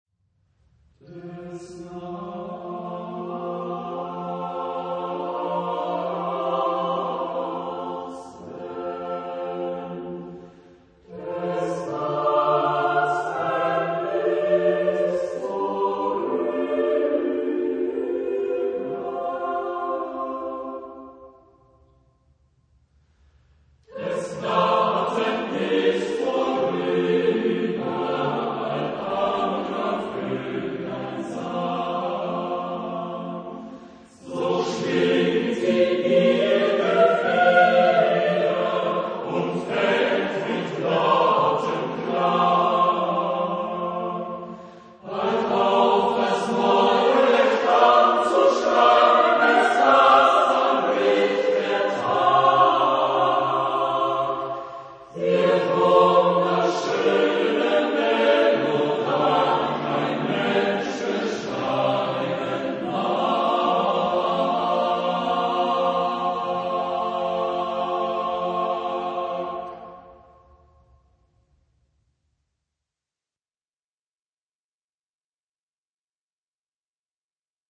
Genre-Style-Forme : Folklore ; Chanson ; Profane
Type de choeur : SATB  (4 voix mixtes )
Tonalité : fa majeur
interprété par Kölner Kantorei